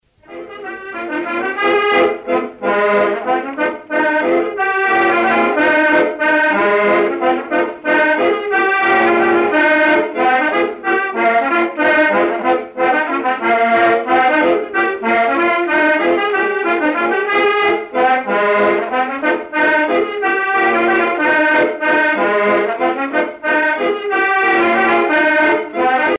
danse : circassienne
Pièce musicale éditée